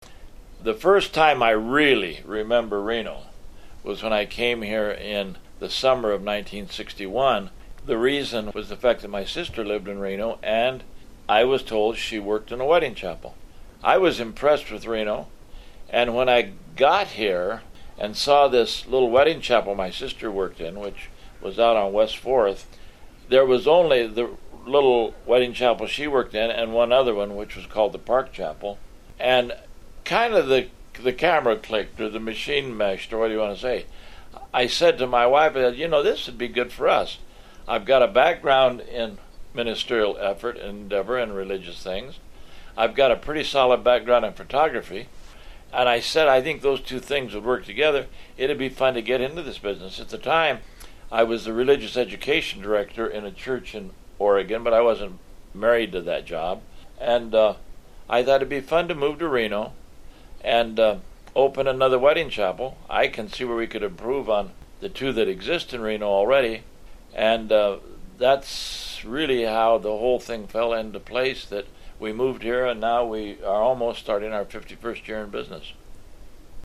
Interviewed in 2012